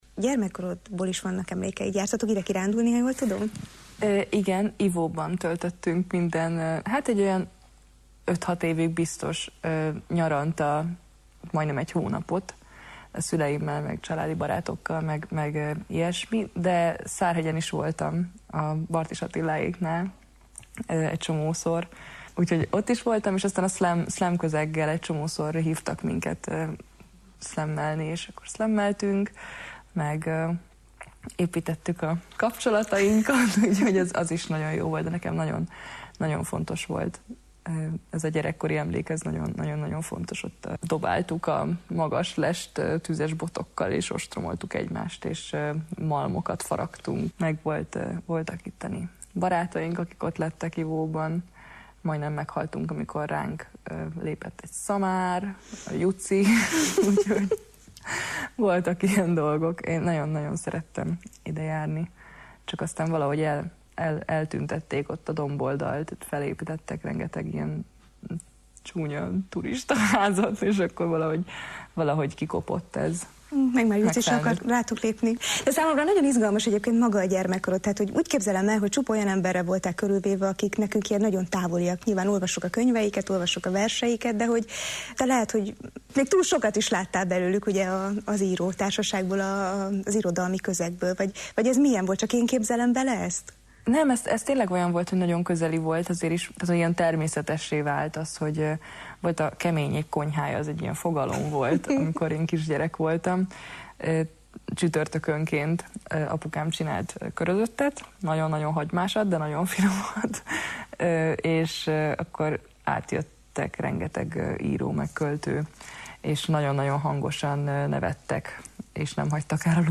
Vele beszélgetünk gyermekkorról, irodalomról, kételyekről, sikerről, női létről.